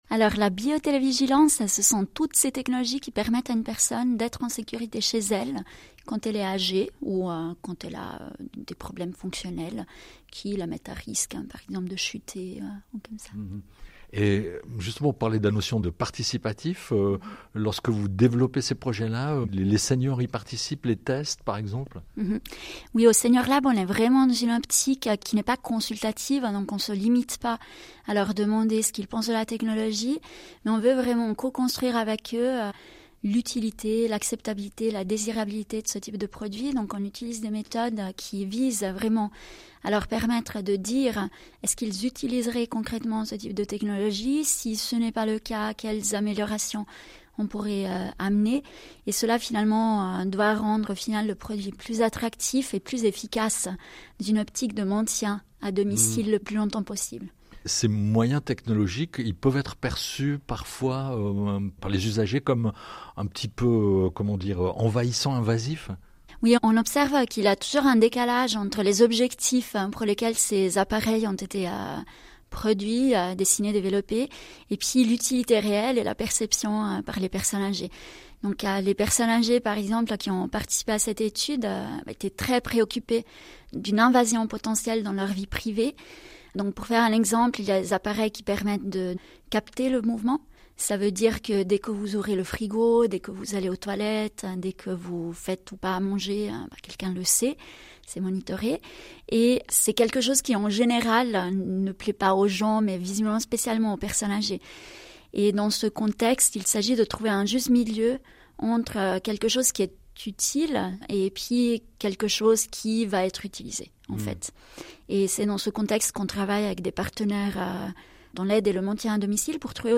Les membres du groupe du pilotage du senior-lab étaient à l’antenne de l’émission CQFD de la RTS en décembre 2024 pour présenter trois projets pilotés par les trois hautes écoles fondatrices de la plateforme.